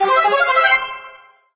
На этой странице собраны звуки из игры Brawl Stars: голоса бойцов, звуки способностей, фразы при победе и поражении.